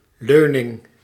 Ääntäminen
IPA: [do]